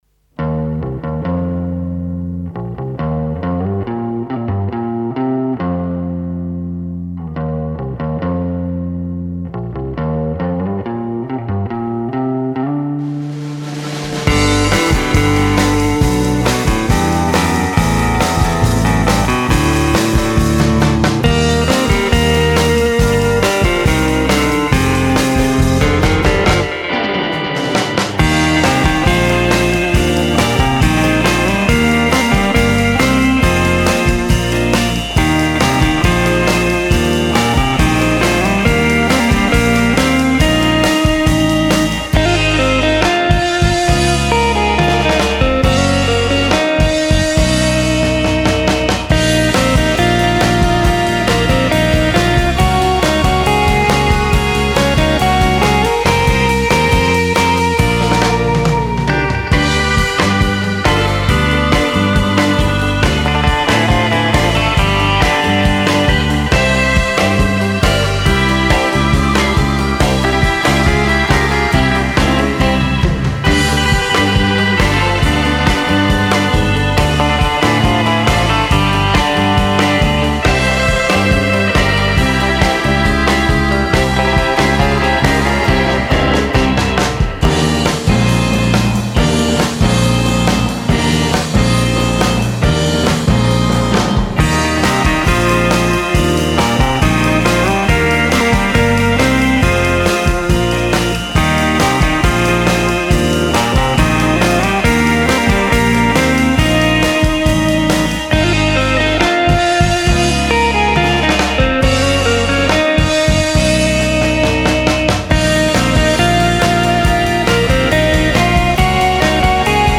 is an iskelmä (pop) music group from Ylöjärvi